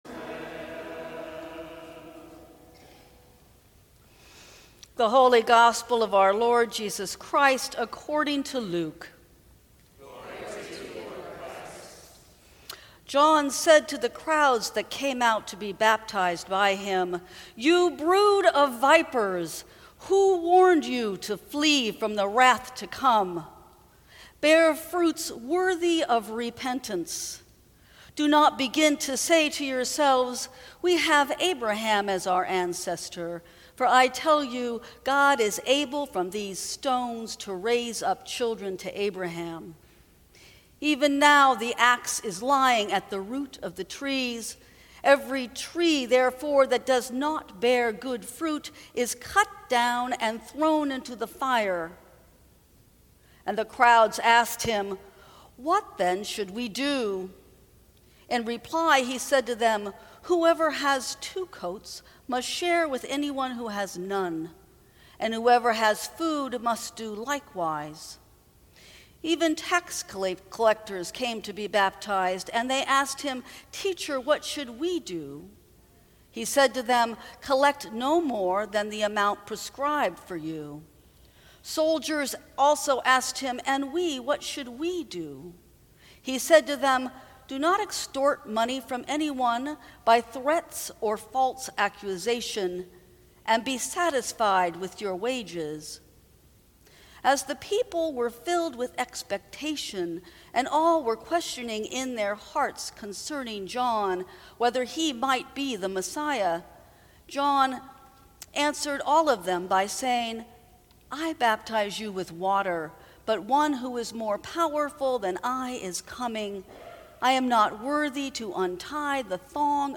Sermons from St. Cross Episcopal Church Rejoice, You Brood of Vipers Dec 24 2018 | 00:15:24 1x 00:00 / 00:15:24 Subscribe Share Apple Podcasts Spotify Overcast RSS Feed Share Link Embed